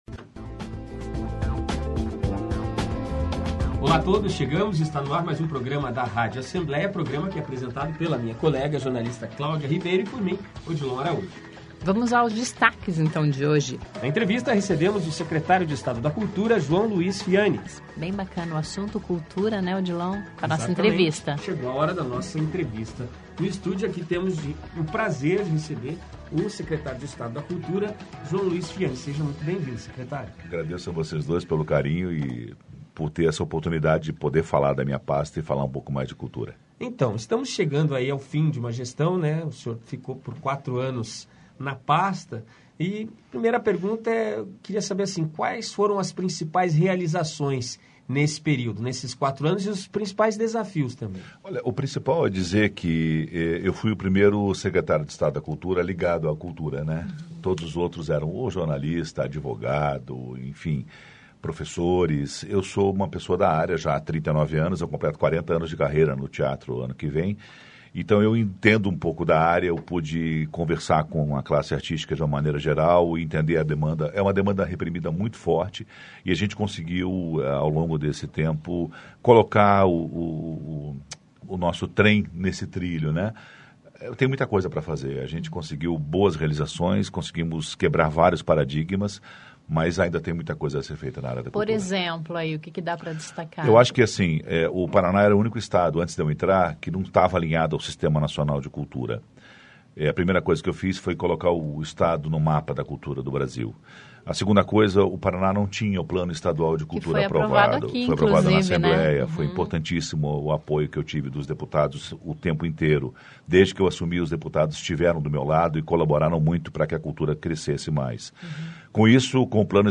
O convidado desta segunda-feira (22) do programa da Rádio Assembleis é o secretário estadual da Cultura, João Luiz Fiani.Entre os temas do bate papo, estão a Escola de Música e Belas Artes, leis de incentivo e o Plano Estadual de Cultura.